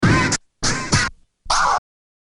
Dj Scratch Tool